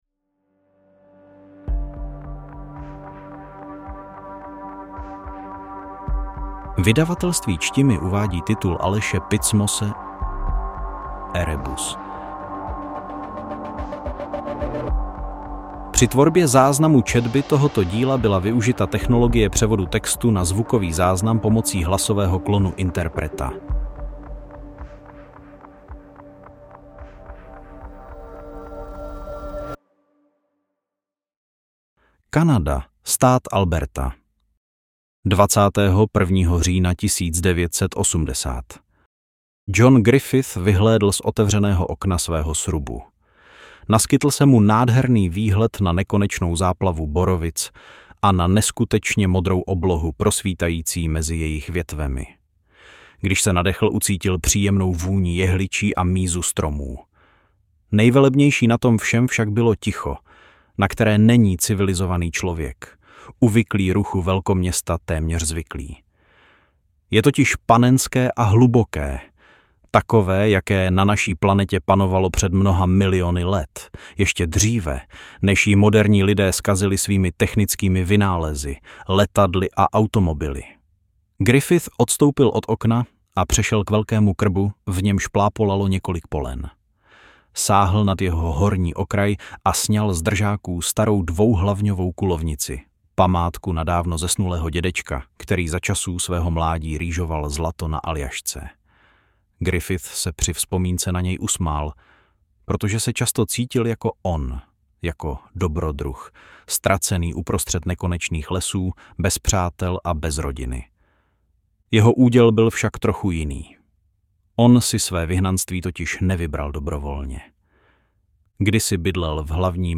AudioKniha ke stažení, 16 x mp3, délka 2 hod., velikost 120,8 MB, česky